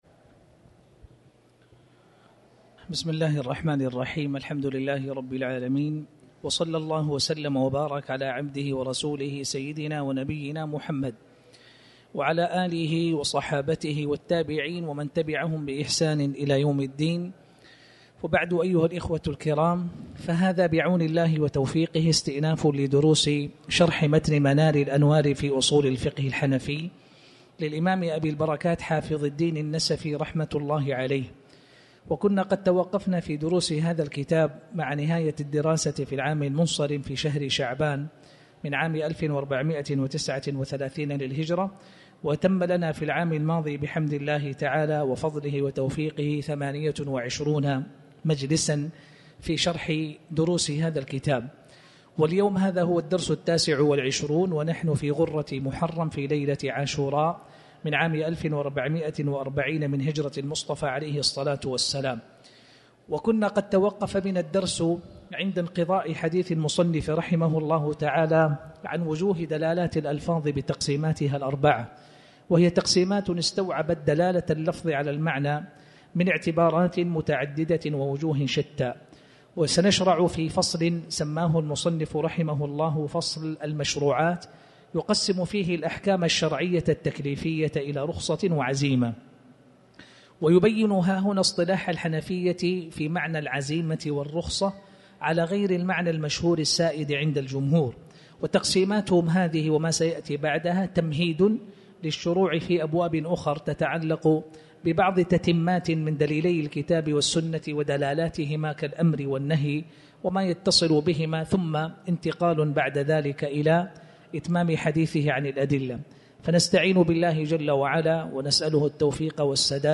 تاريخ النشر ٩ محرم ١٤٤٠ هـ المكان: المسجد الحرام الشيخ